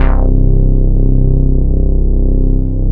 Techno US Tekno's 1 Oscar C1 us_teckno_s 94 KB